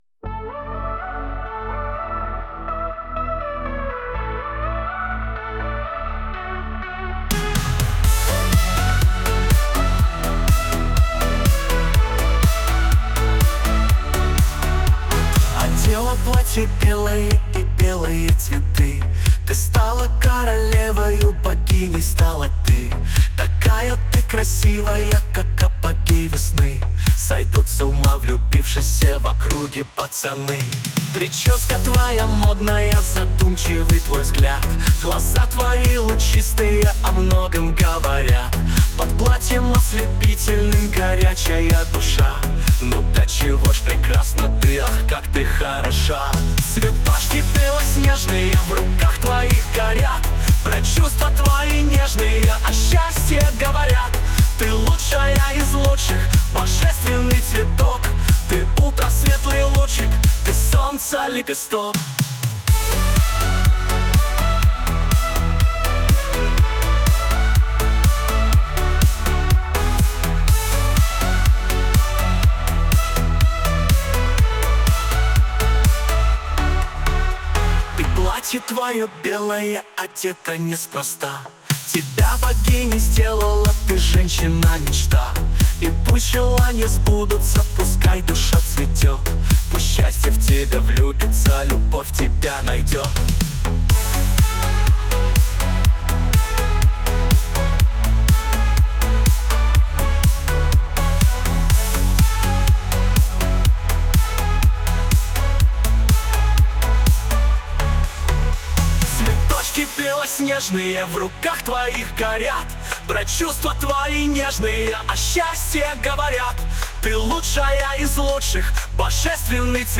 12 чудесная песня... нежная и тёплая